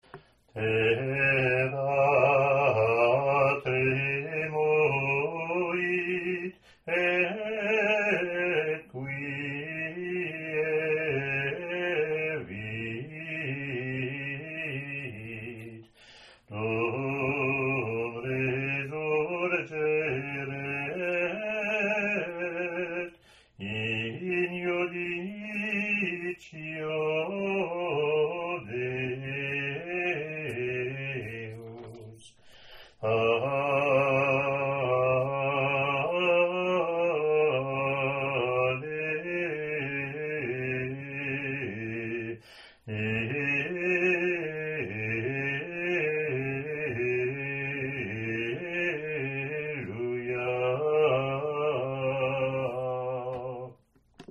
Latin antiphon , professional recording below)